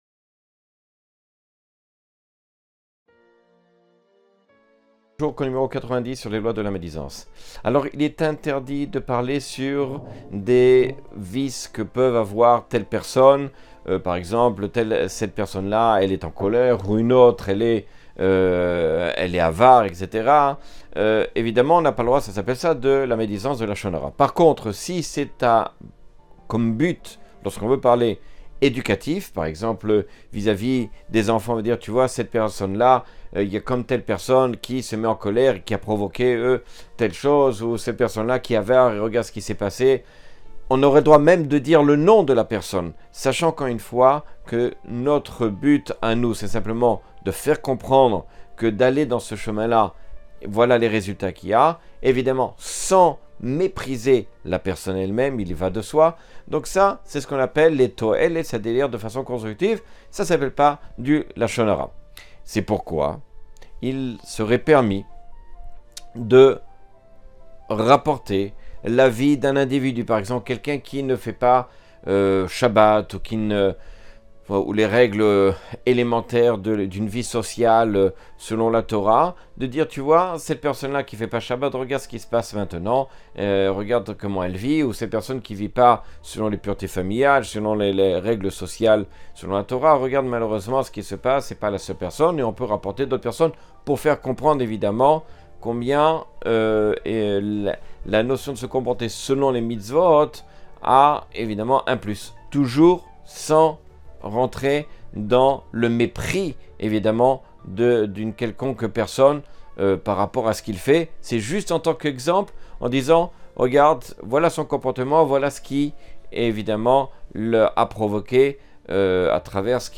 Cours 90 sur les lois du lashon hara.